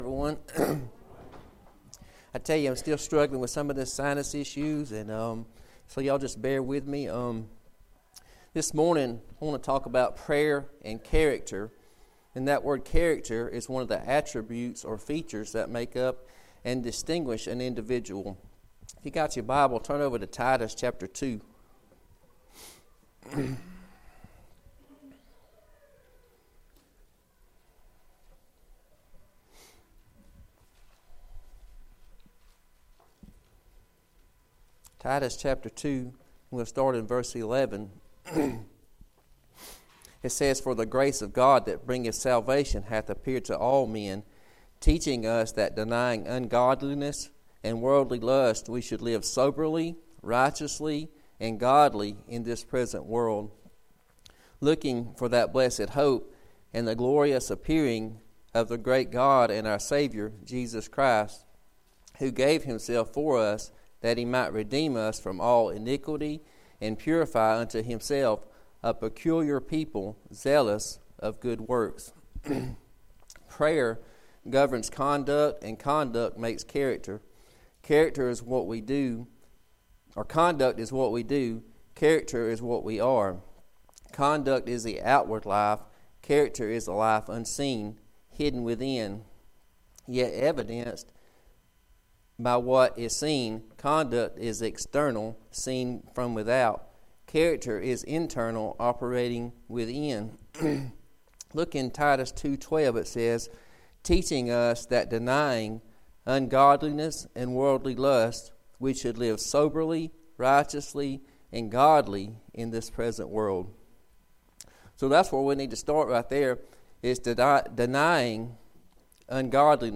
Passage: Titus 2:11-14, Phil 2:13-16, Rom 8:1-4, Isa 58:8-11, 1:2-4, 14-17, Eph 5:15-16 Service Type: Sunday School